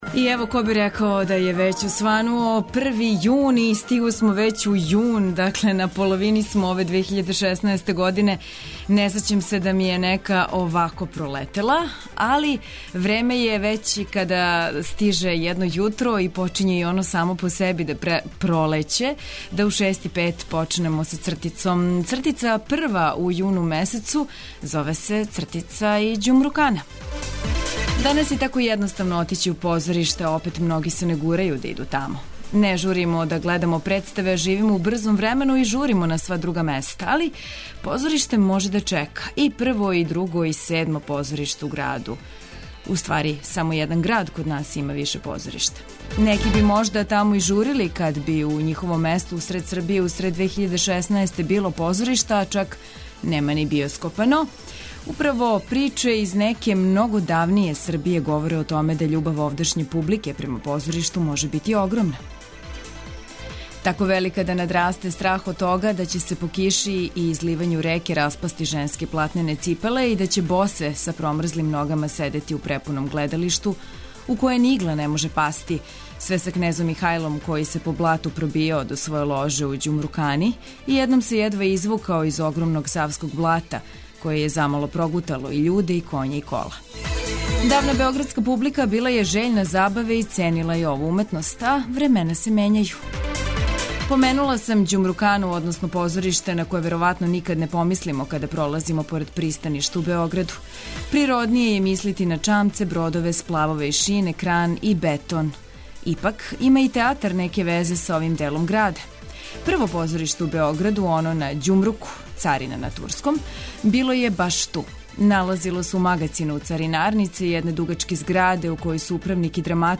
Но, да се вратимо на музику у "Устанку" и песме којих се вреди сетити.